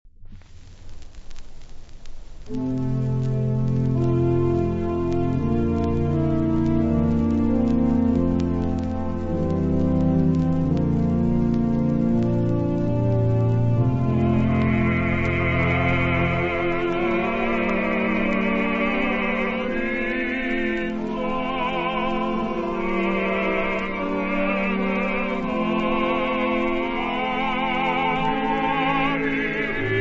basso
. contralto
. organo
. sopranista
tenore
• mottetti
• Motet